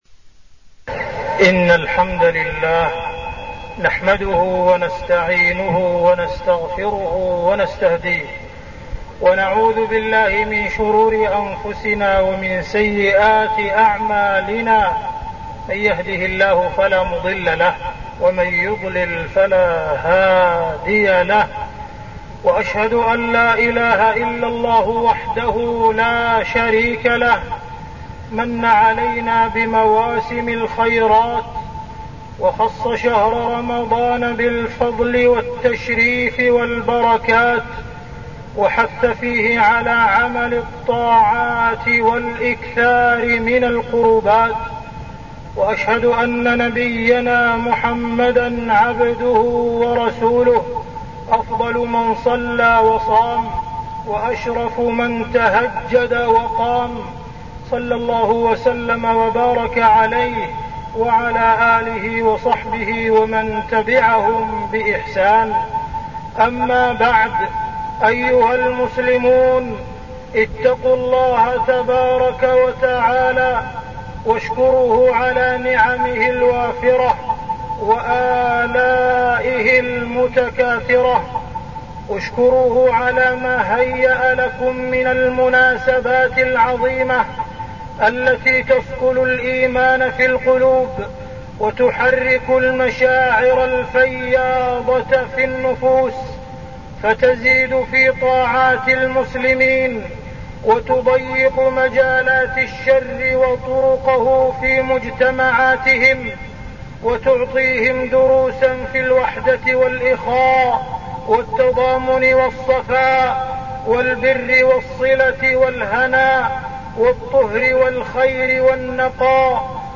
تاريخ النشر ٩ رمضان ١٤١٢ هـ المكان: المسجد الحرام الشيخ: معالي الشيخ أ.د. عبدالرحمن بن عبدالعزيز السديس معالي الشيخ أ.د. عبدالرحمن بن عبدالعزيز السديس فضل شهر رمضان